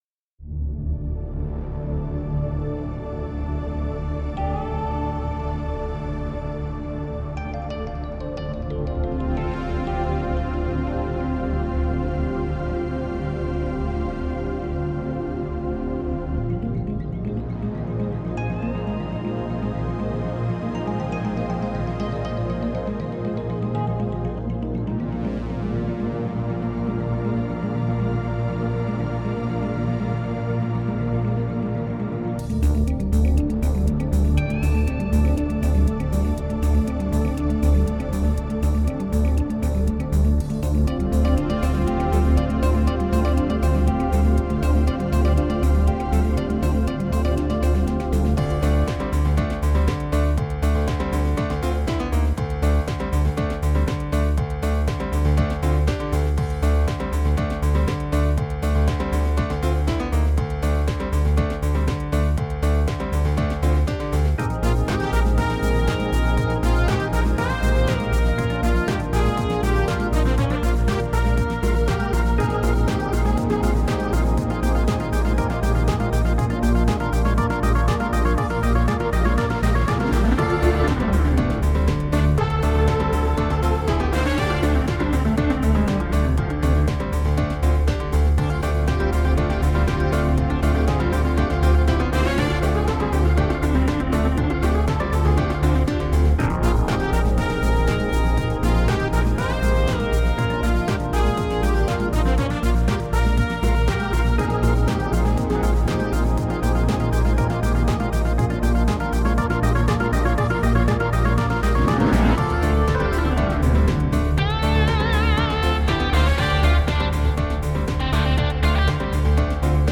– whole tune with all lead voices
Lead-Audio (part of the pack):